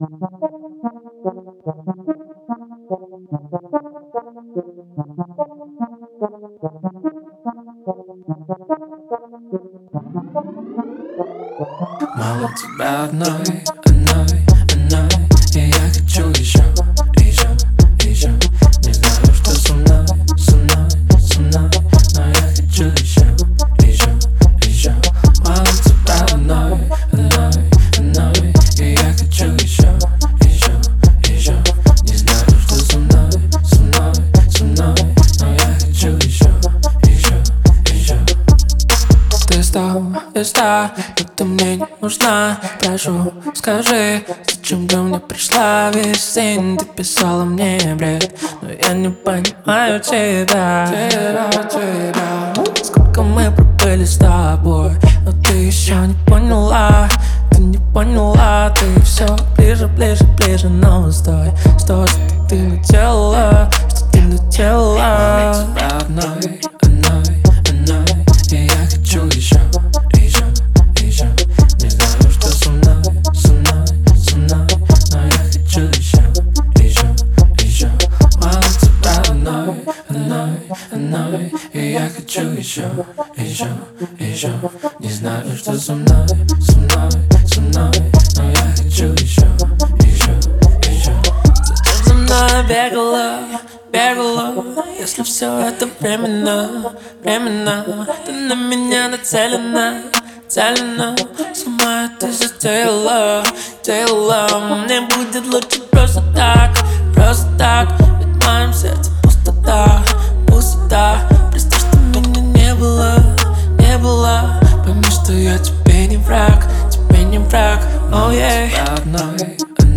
зажигательная песня